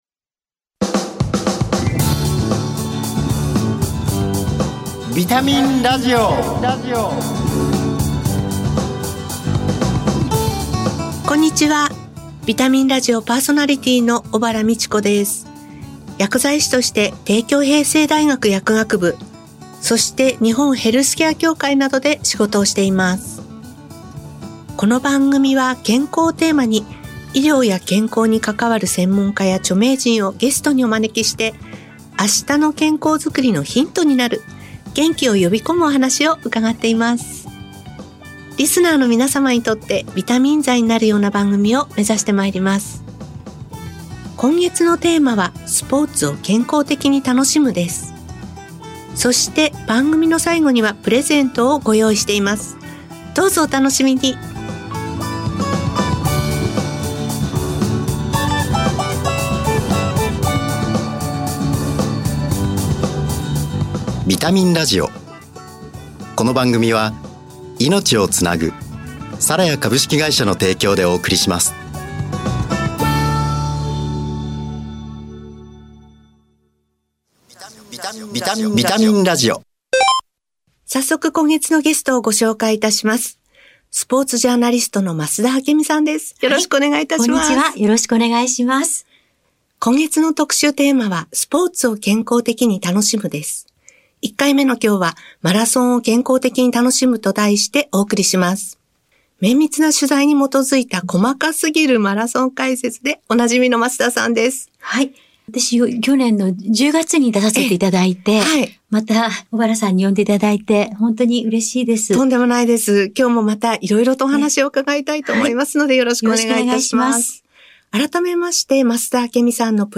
「健康」をテーマに、元気になる情報をお届けします。医療従事者など専門家がゲストに登場。